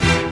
neptunesstringSYNTH.wav